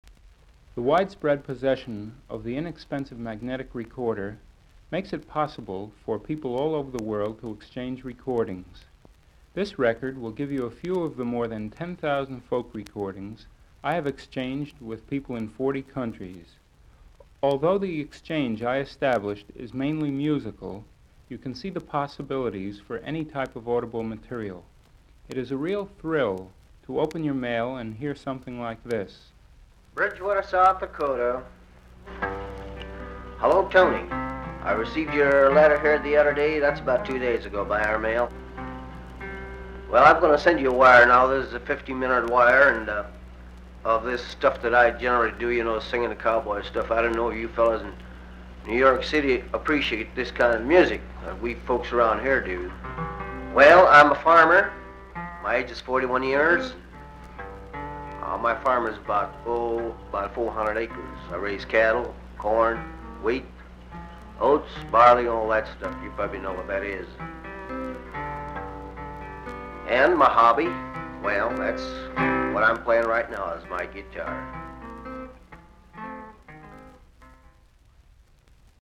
01. Introduction - South Dakota farmer